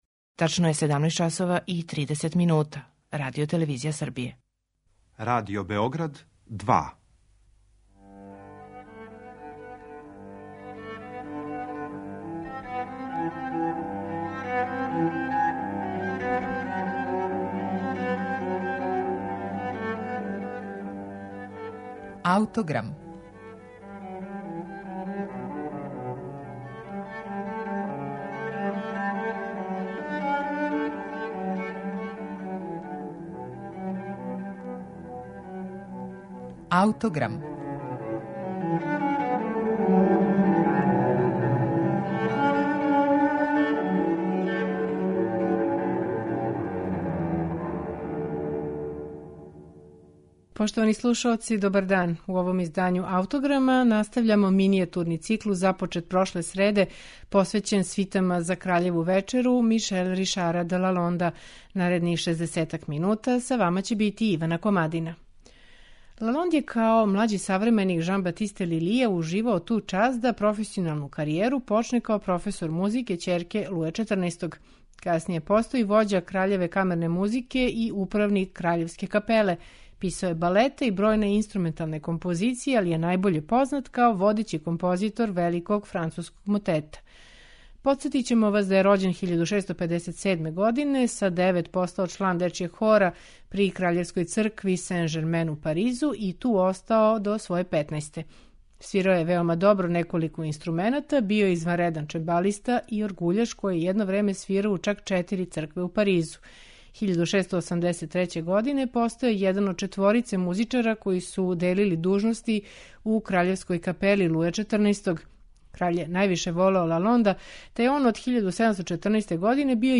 поставши стубови француске барконе музике.